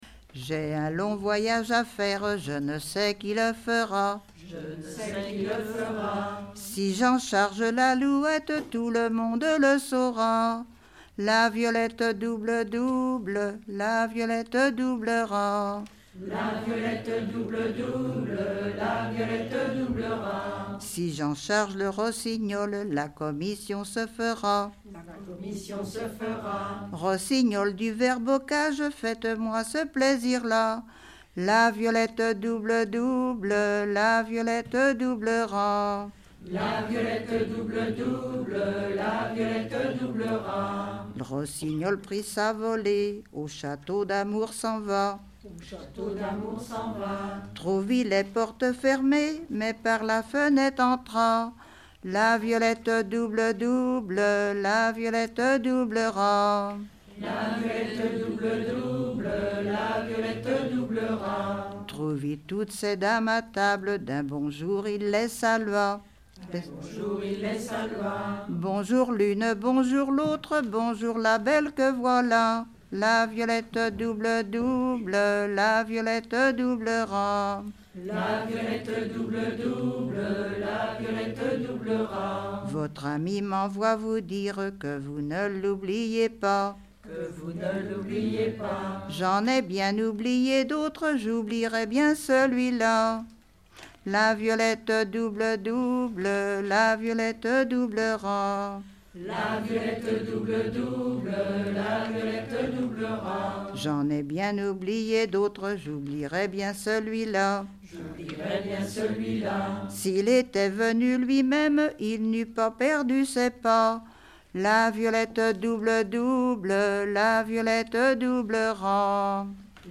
Genre laisse
Chansons
Pièce musicale inédite